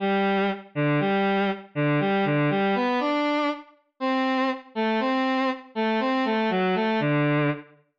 eine-kleine-bass-1.wav